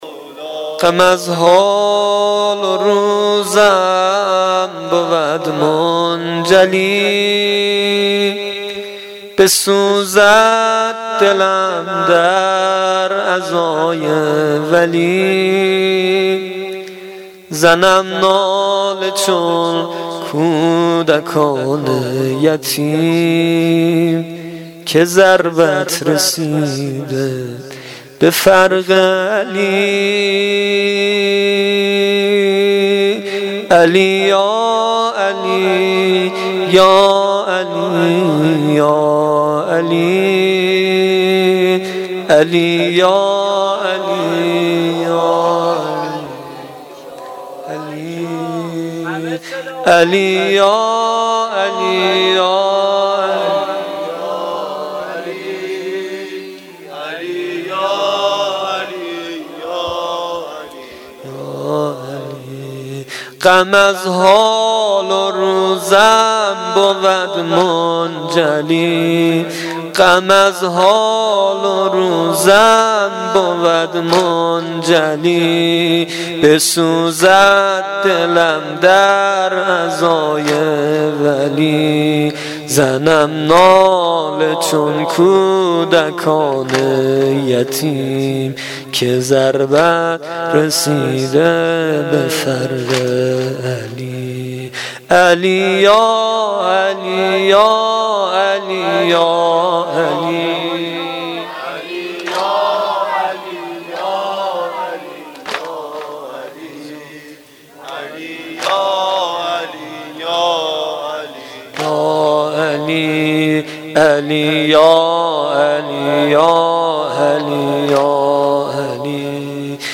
واحد سنگین شب 21 ماه رمضان